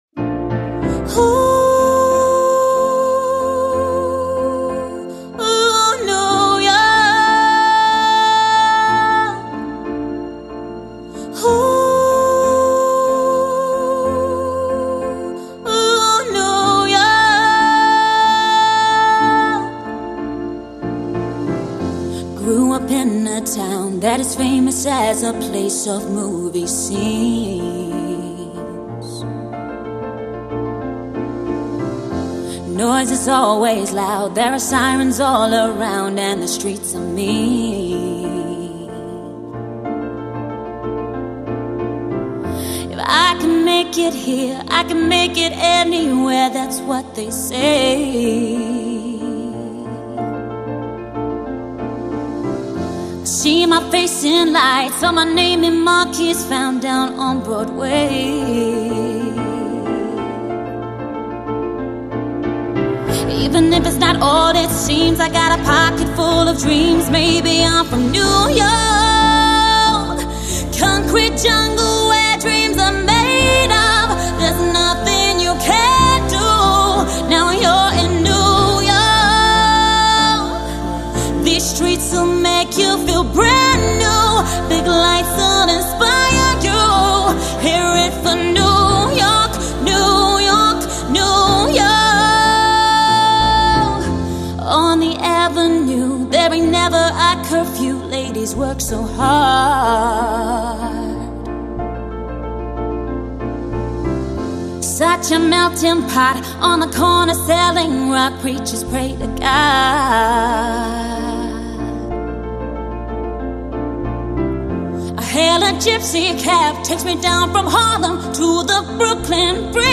female singer/songwriter
With a beautiful voice and warm personality